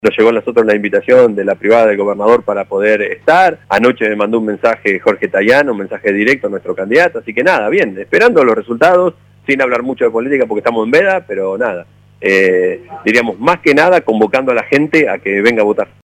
El intendente Pablo Garate sufragó poco después de las 9:00 y luego, en contacto con el móvil de LU 24 sostuvo “Hoy tenemos una responsabilidad muy importante, invito a la gente a que participe porque es importantísima, ya que lo que pasa en el Congreso se refleja en los municipios”, dijo.